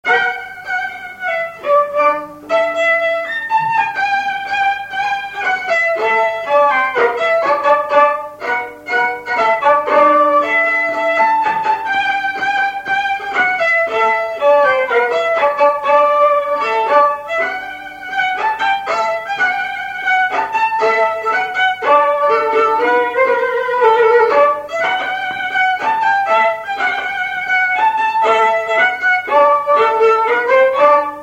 Polka
Résumé instrumental Usage d'après l'analyste gestuel : danse
Pièce musicale inédite